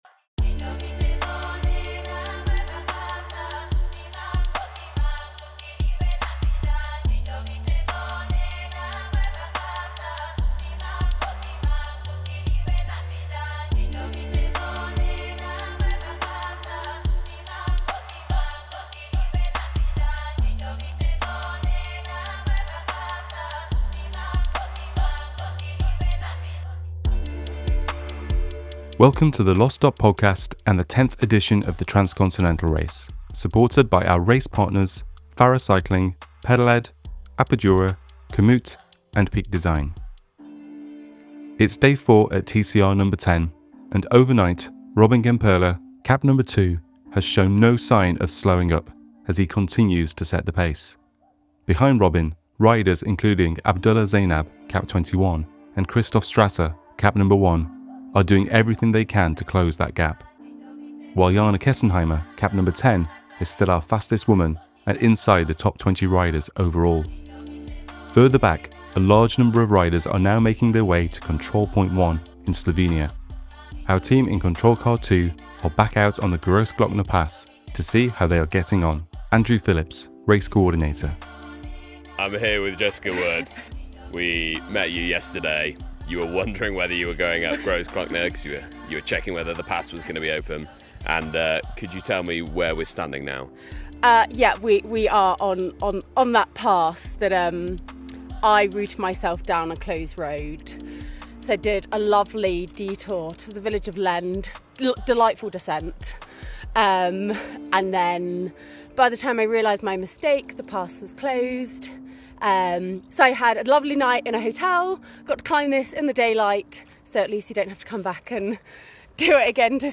waits for the chasing pack at the top of the Bjelašnica on Parcours 2